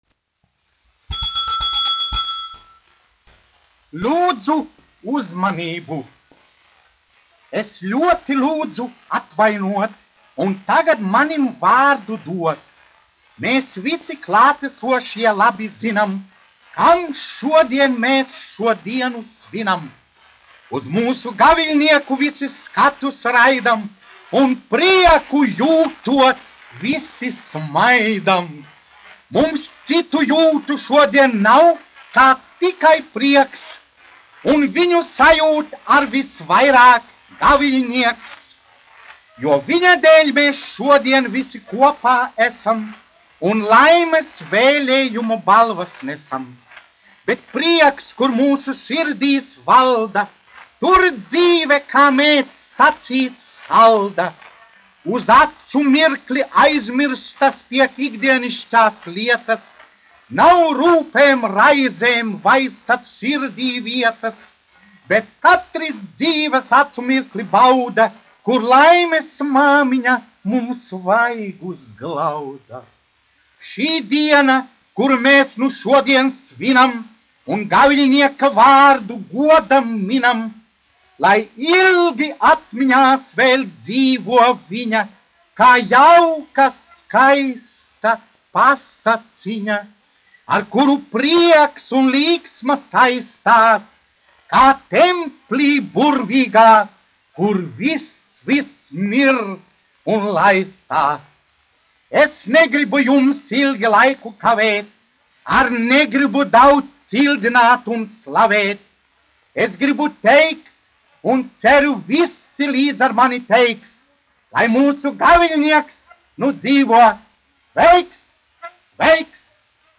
1 skpl. : analogs, 78 apgr/min, mono ; 25 cm
Monologi ar mūziku